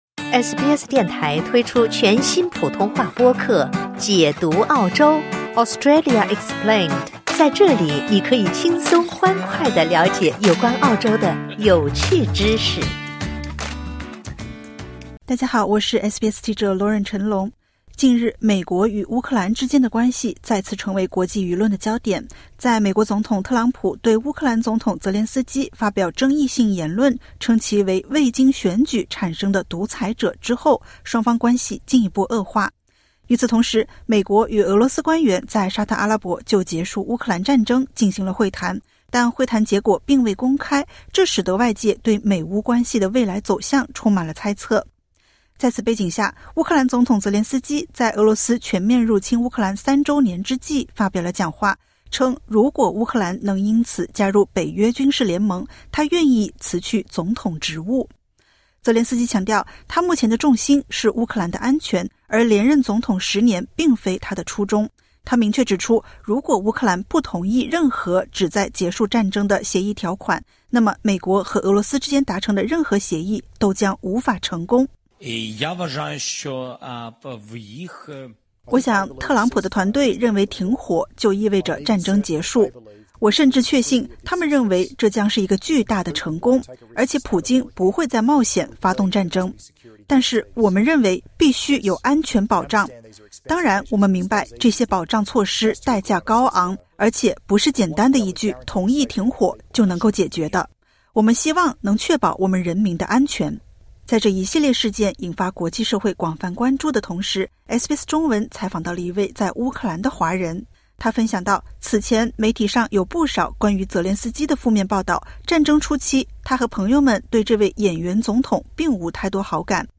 乌克兰当地华人如何看待泽连斯基与美乌关系？点击 ▶ 收听完整采访。